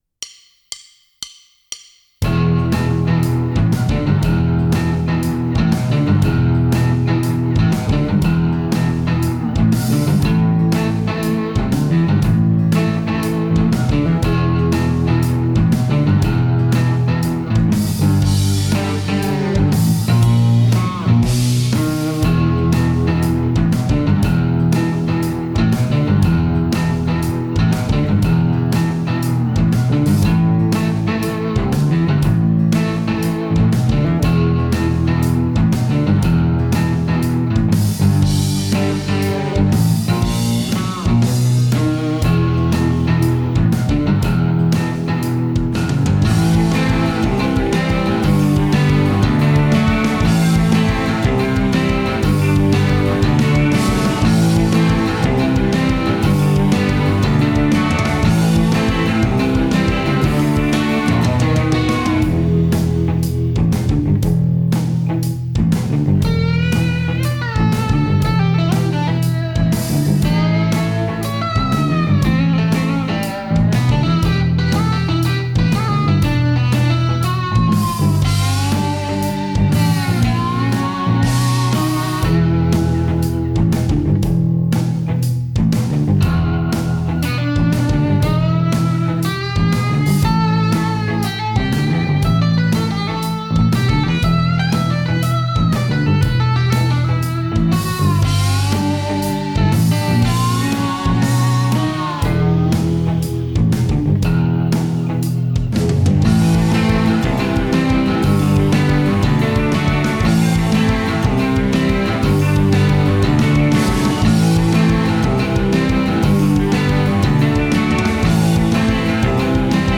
Expressive acoustic slide guitar for Kontakt